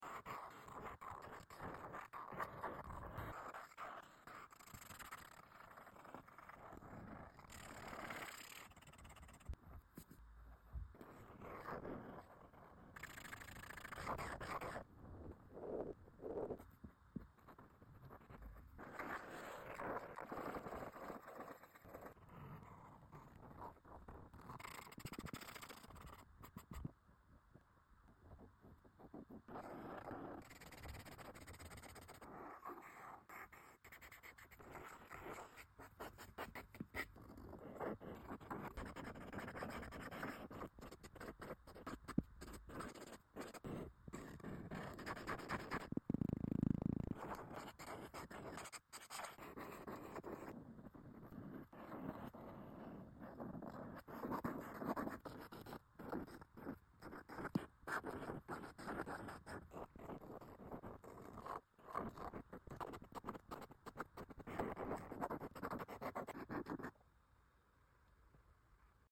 ASMR my August calendar! 🤠🏜🍒🌾 sound effects free download